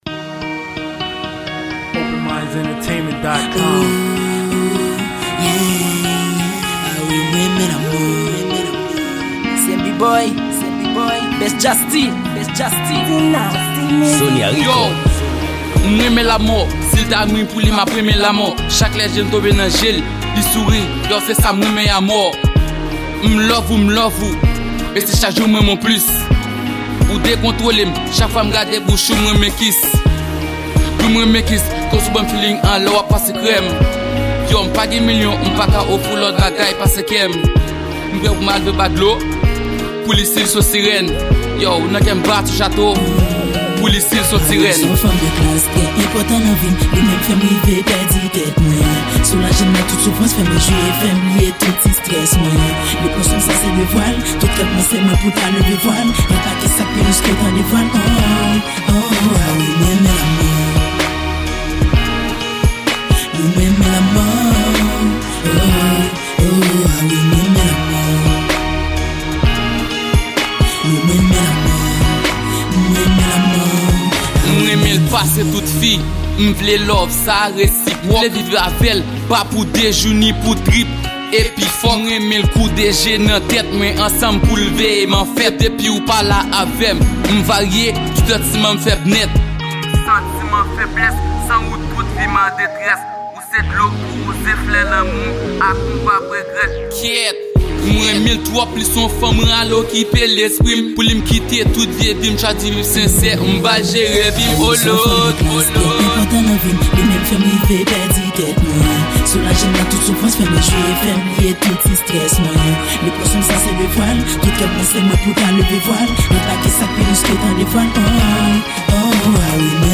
Genre: Rap.